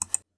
BlockMove.wav